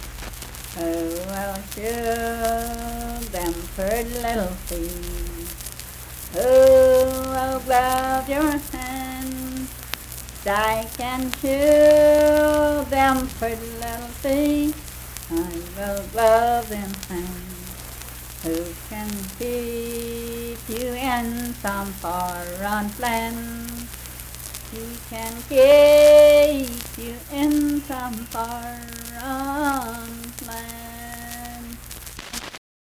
Unaccompanied vocal music
Verse-refrain 1(6).
Performed in Big Creek, Logan County, WV.
Voice (sung)